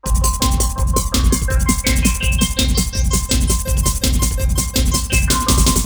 __BEEP POP 2.wav